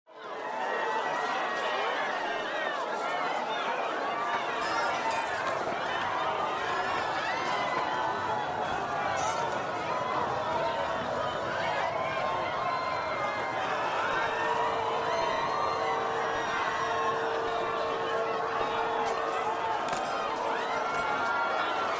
Шум переполоха людей